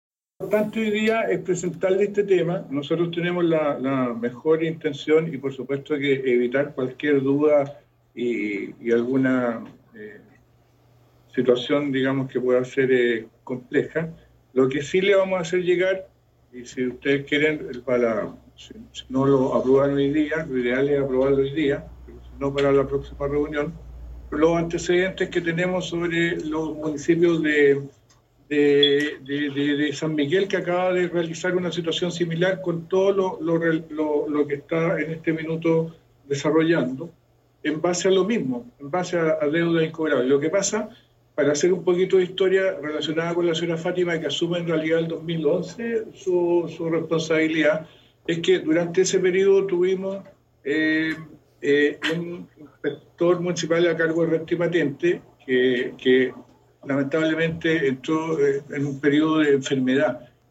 Por su parte el Alcalde Rolando Mitre indico respecto a esa observación que el interés nuestro es presentar este tema y despejar todas las dudas de un tema que pueda ser compleja.